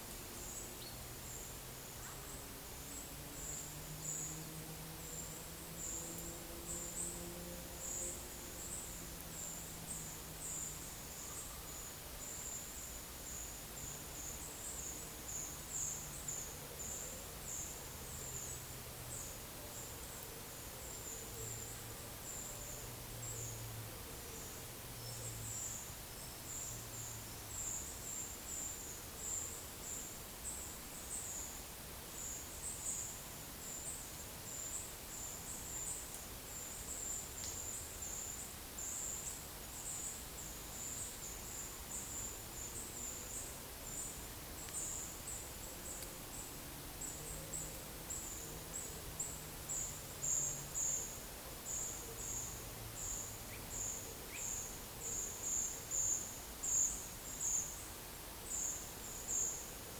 Monitor PAM - Renecofor
Certhia brachydactyla
Turdus iliacus
Certhia familiaris
Regulus ignicapilla
Coccothraustes coccothraustes